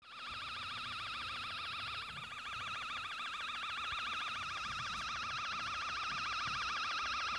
TLV320DAC3100: Permanent noise (like an alarm) in Speaker added to audio signal
I'm trying to manage to play audio to a 8 ohm speaker using TLV320DAC3100 TI DAC+amplifier.
My problem is that even if I do not play any sound, I still have a noise I can't rid of.